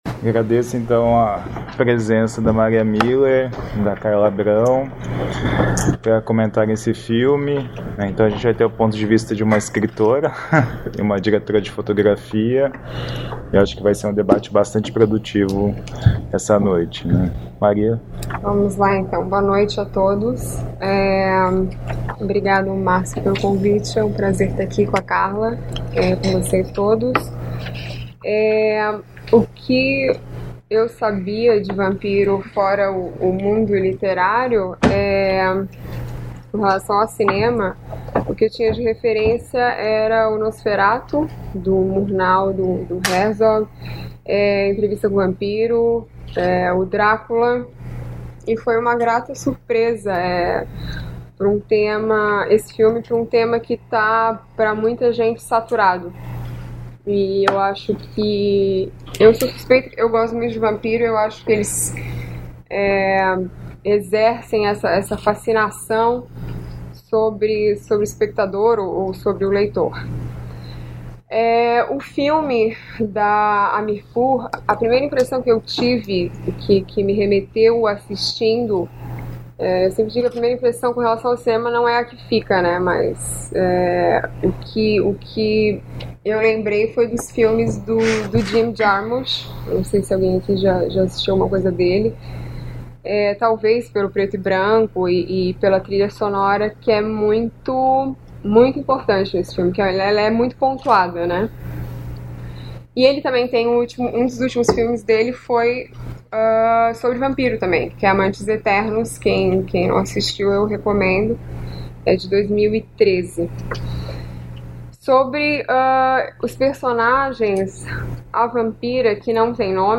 Comentários das debatedoras convidadas
na sessão de exibição e debate do filme